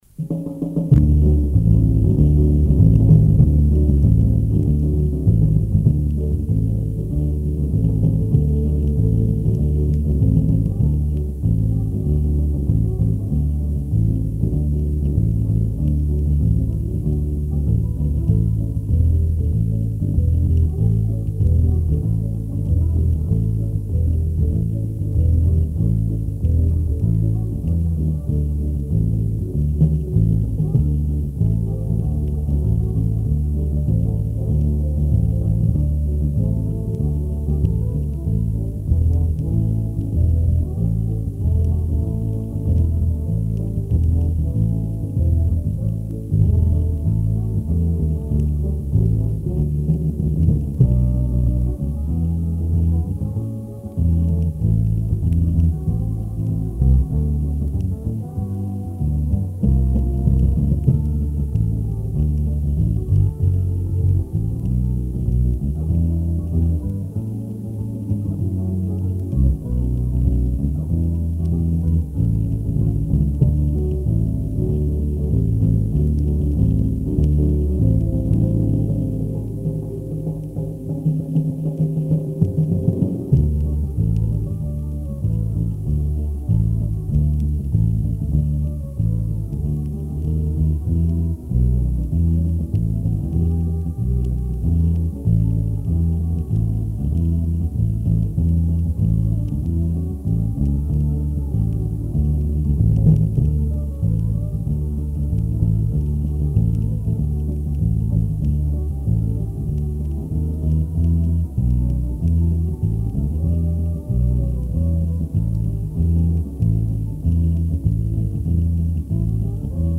Это оцифровка с бобины.